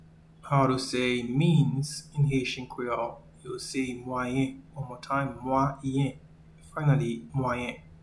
Pronunciation:
Means-in-Haitian-Creole-Mwayen.mp3